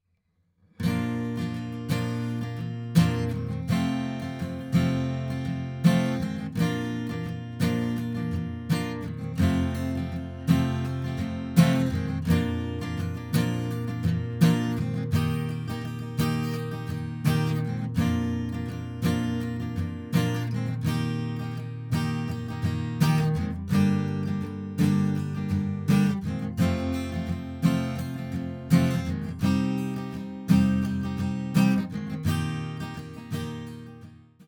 Batteria Acustica
ACOUSTIC-HERITAGE-DRY.wav